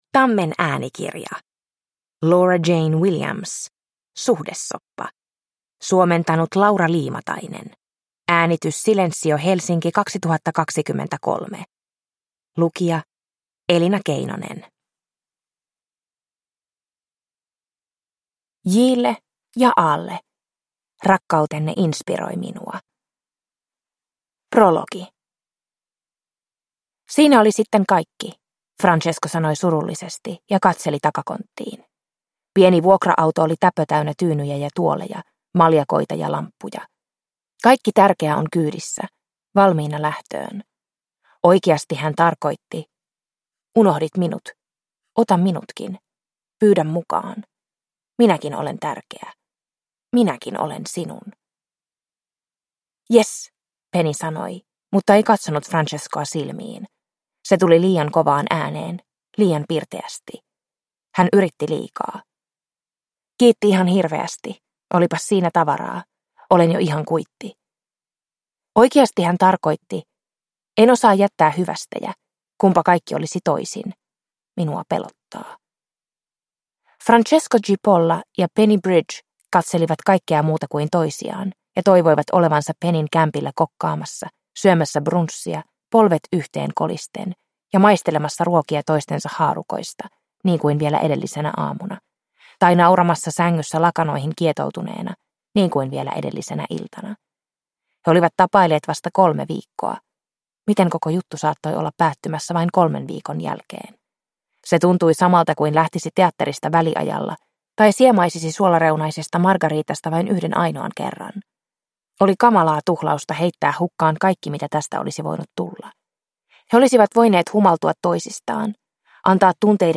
Suhdesoppa – Ljudbok – Laddas ner